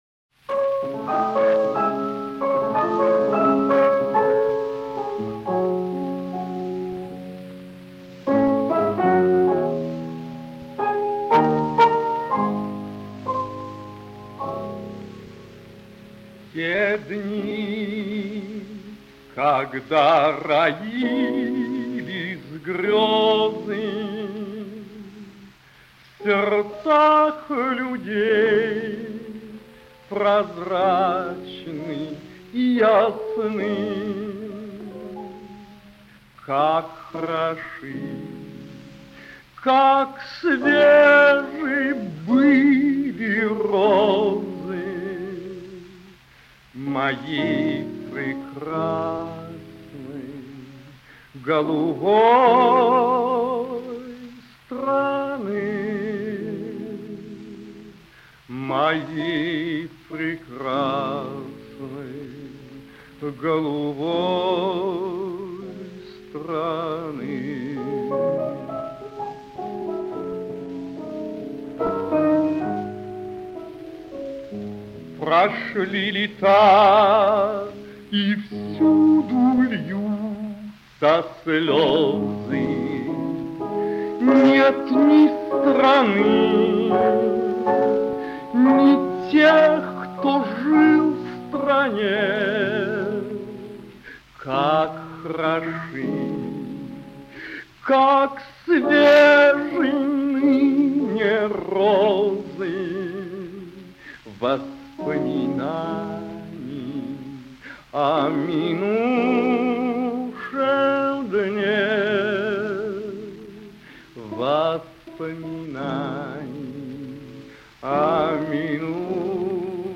Жалисная песня... cry 12 friends 31